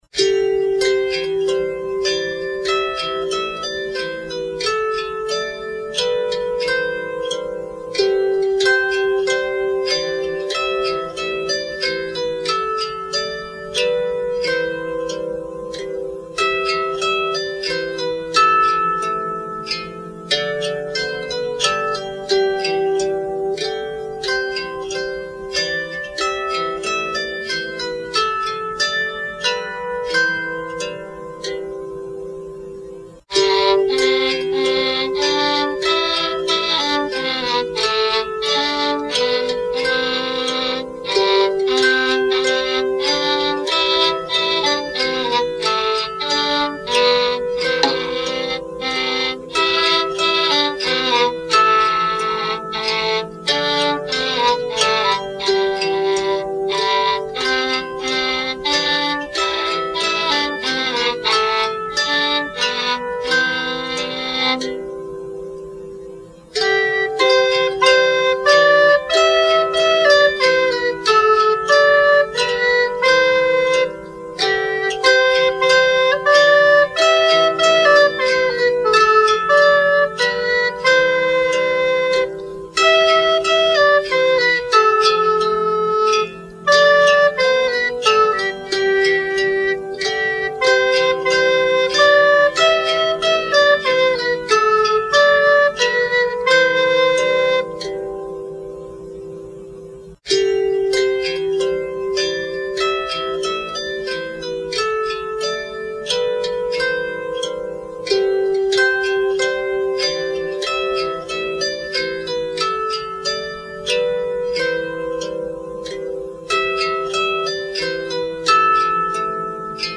This is a German folk song, urging children to “Get up!”
However, this is a beautiful melody, and I hope you enjoy hearing it on zither, viola, and alto recorder!